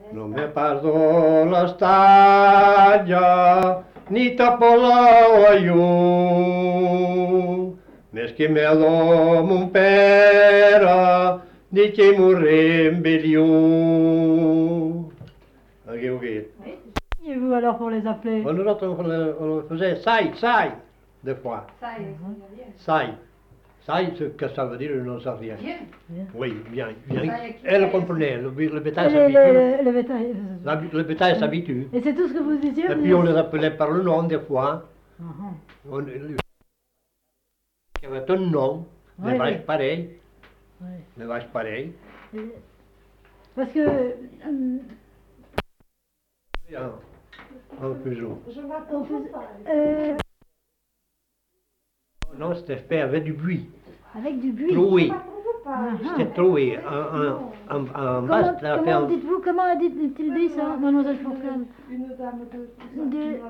Lieu : Lesponne (lieu-dit)
Genre : chant Effectif : 1 Type de voix : voix d'homme Production du son : chanté